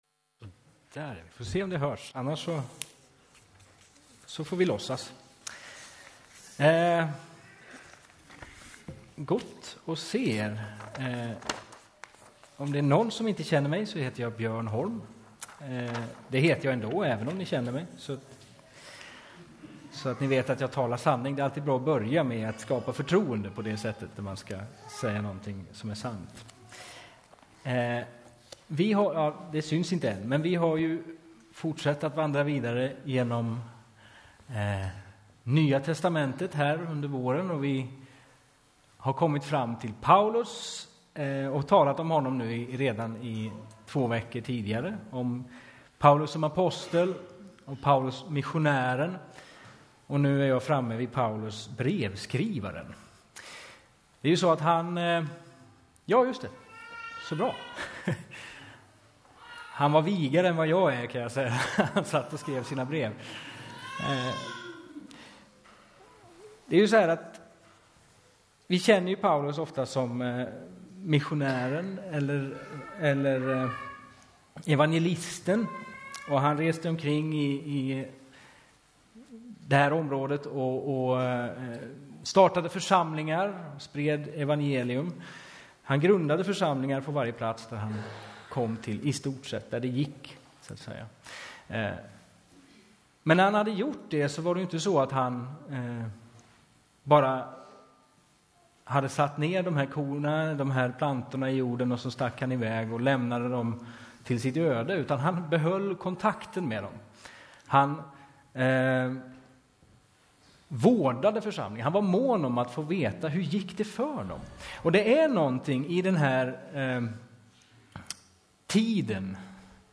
Predikoserie: Genom NT (våren 2018)